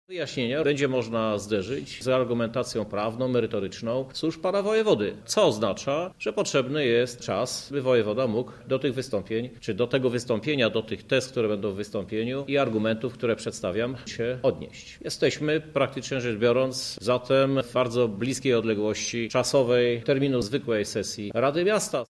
-mówi Krzysztof Żuk, prezydent Lublina.